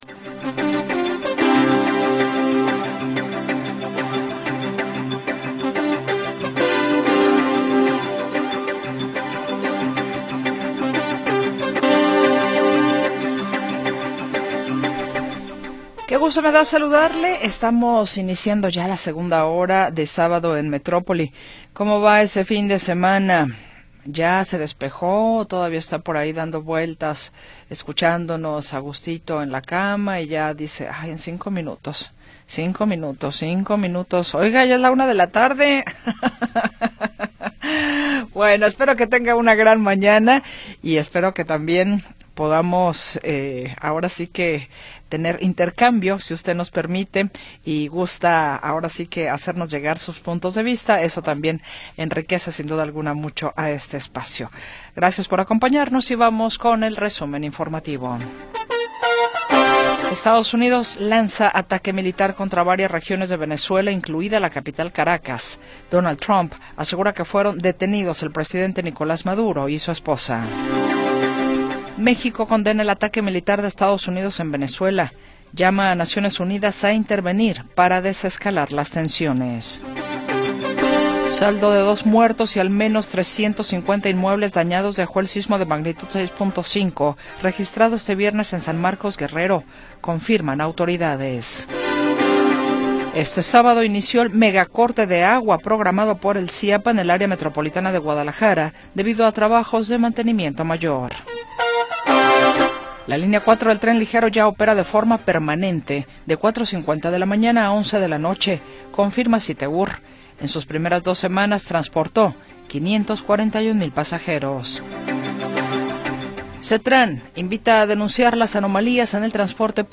Noticias y entrevistas sobre sucesos del momento